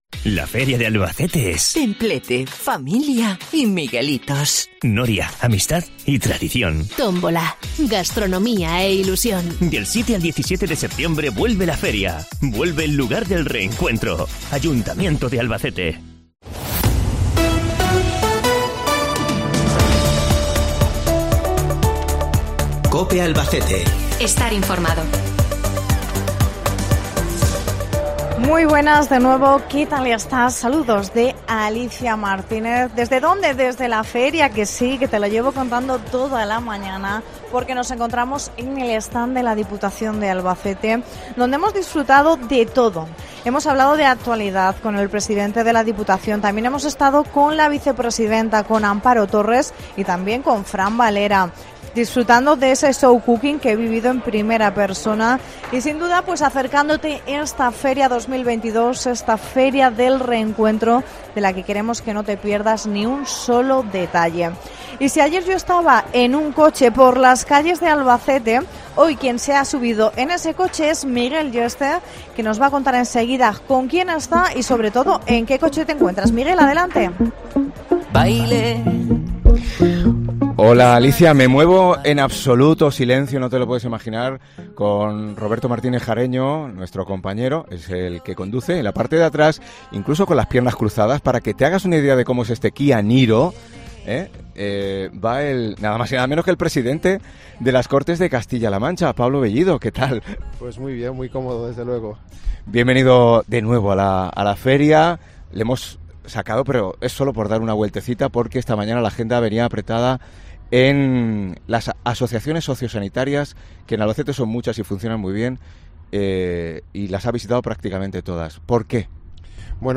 AUDIO: Desde la Feria, con un Kia Niro eléctrico enchufable, hablamos con el presidente de las Cortes de Castilla-La Mancha, Pablo Bellido